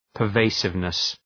Προφορά
{pər’veısıvnıs}